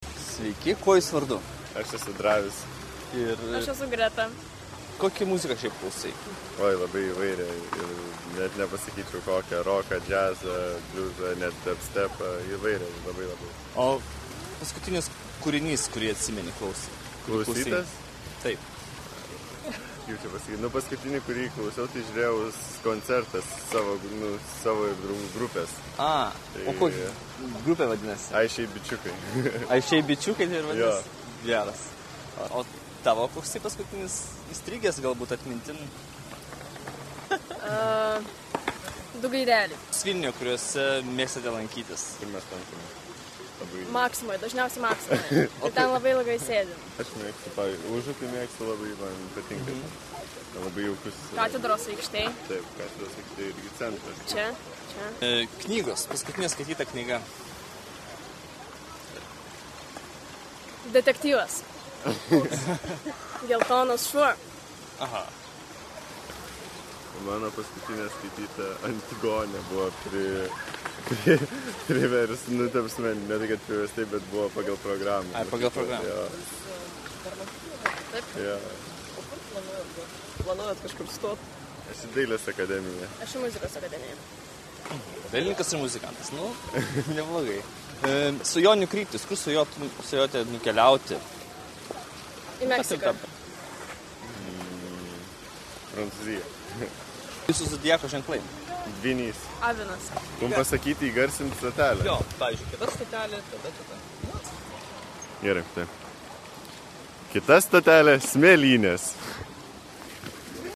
Interviu – įrašo pabaigoje.